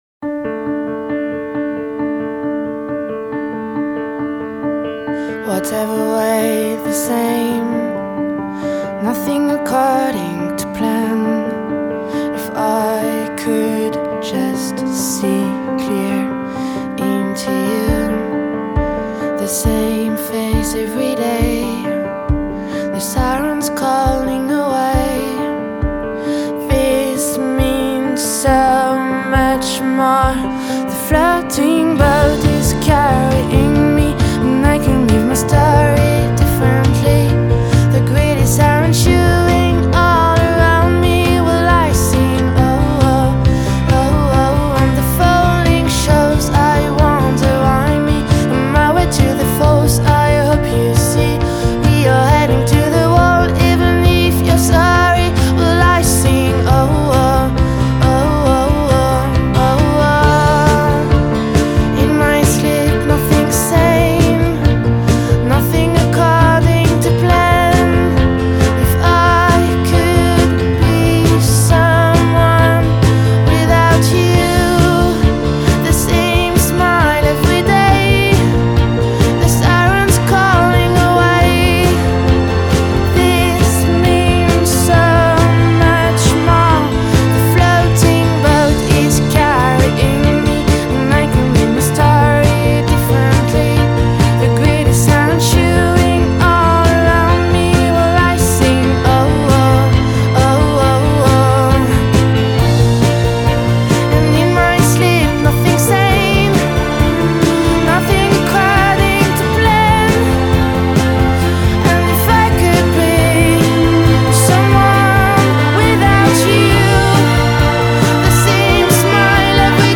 Genre: Indiepop/Piano Rock/Female Vocal